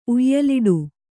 ♪ uyyaliḍu